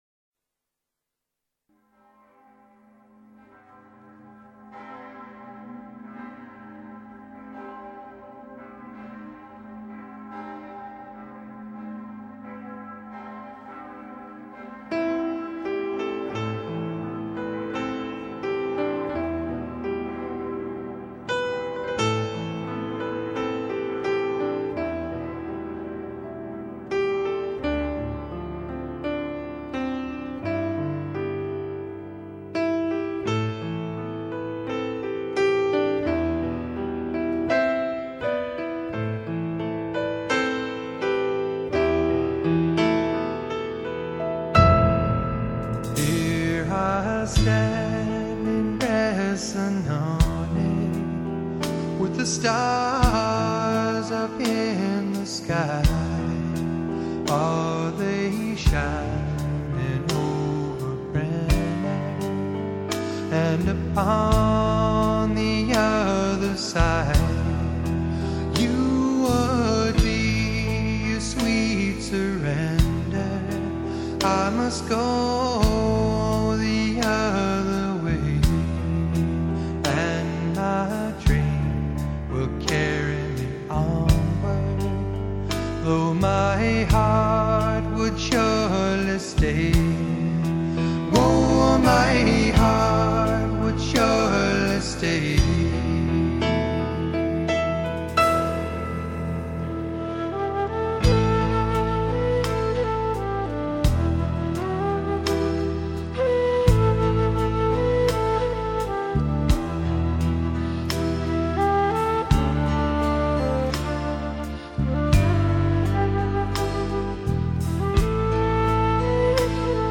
极至完美的音质 娓娓动听的旋律 尽情感受极品天碟
音质爆好，节奏感强，最适合驾车时听，建议刻碟哦。